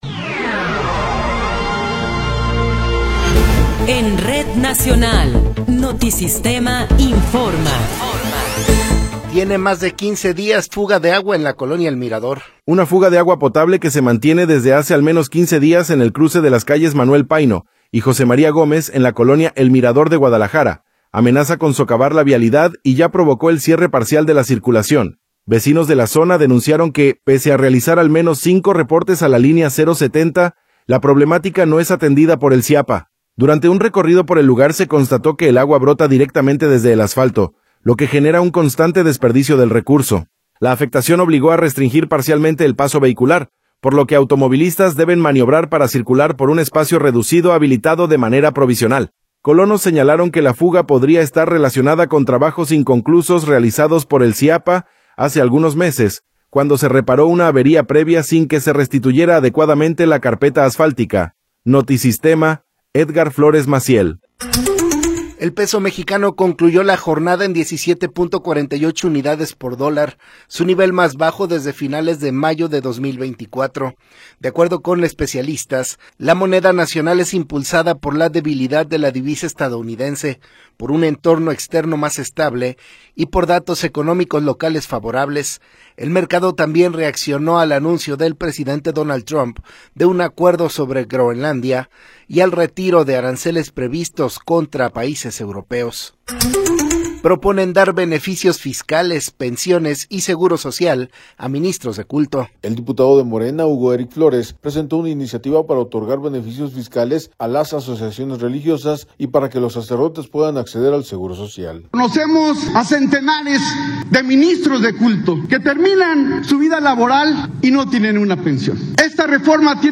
Noticiero 18 hrs. – 21 de Enero de 2026